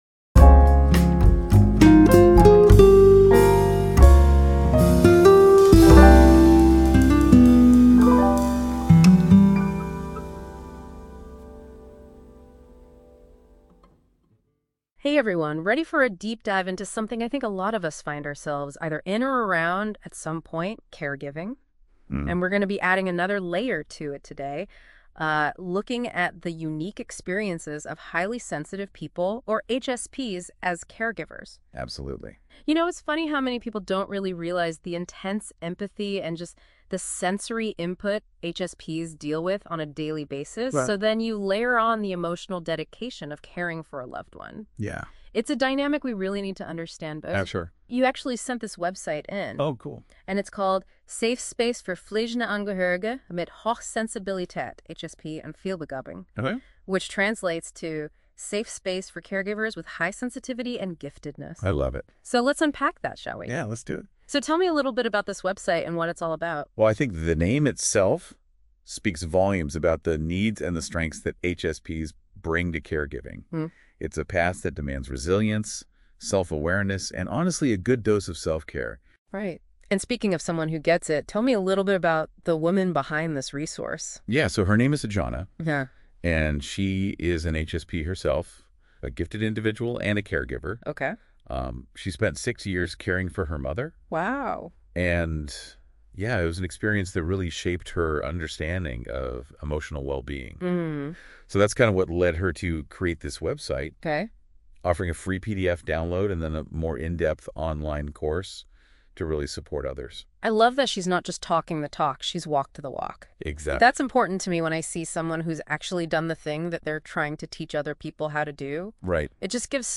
I did it! Podcasts mit NotebookLM Audio Overviews
AI-Podcast-EVEEE-Kurs-Einfuehrung.mp3